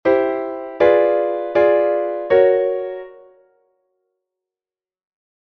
2. Von C-Dur zu Fis-Dur
Enharmonische Modulation C-Fis
Enharmonische-Modulation-C-Fis-Audio.mp3